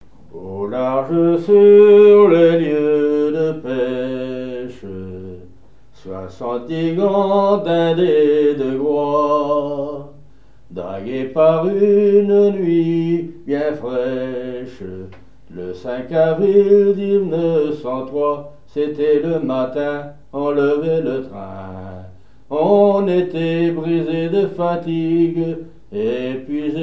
La pêche au thon et des chansons maritimes
Pièce musicale inédite